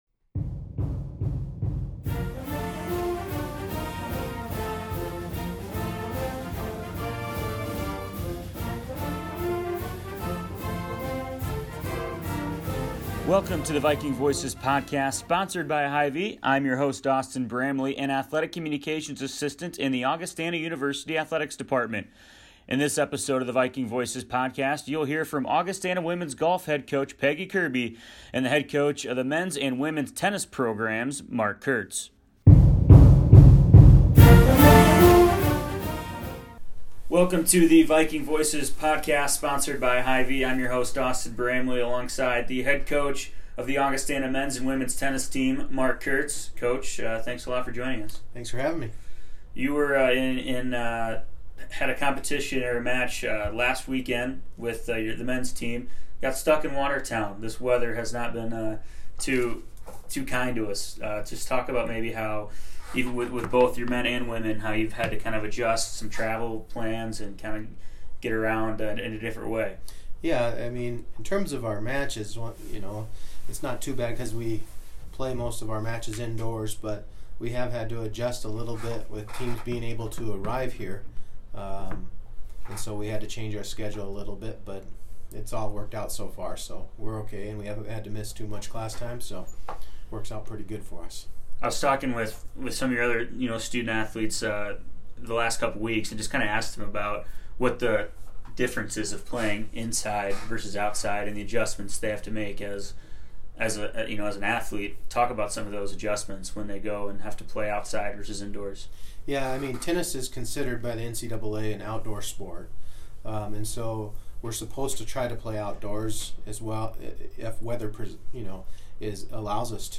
The Viking Voices podcast, which features Augustana University coaches, student-athletes, athletic staff members and other guests, is available on iTunes. The podcast focuses on stories surrounding Augustana Athletics.